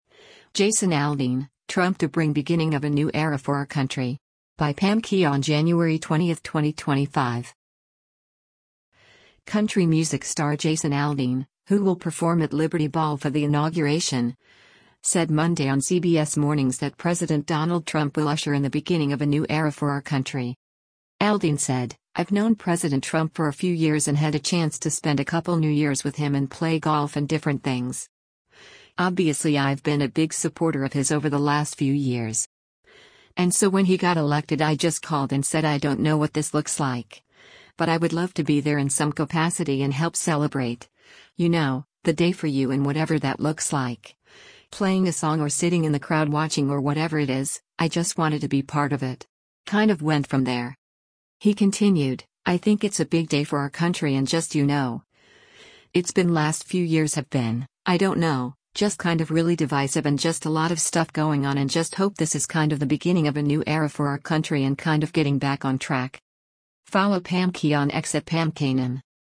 Country music star Jason Aldean, who will perform at Liberty Ball for the inauguration, said Monday on CBS Mornings that President Donald Trump will usher in the “beginning of a new era for our country.”